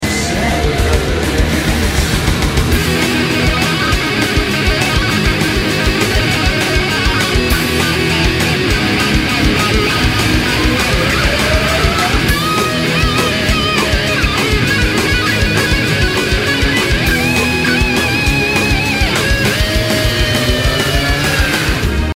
guitar tutorial